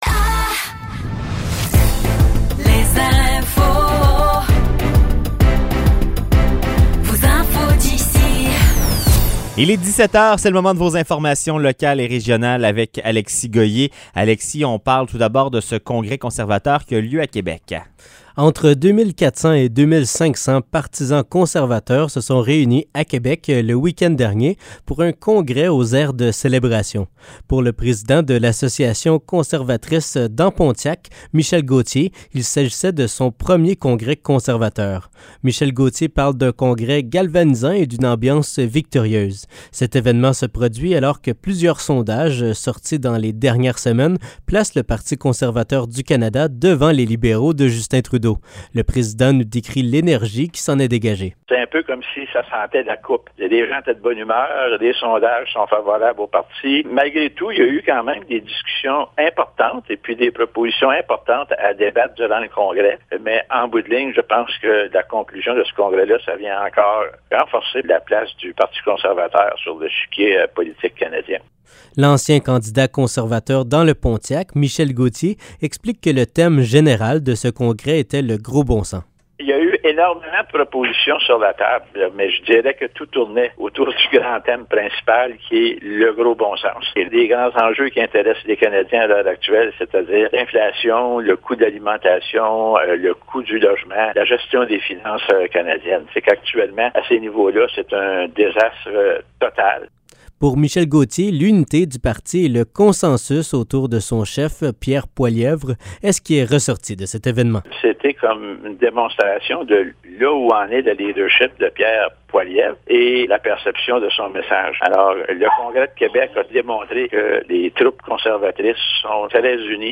Nouvelles locales - 12 septembre 2023 - 17 h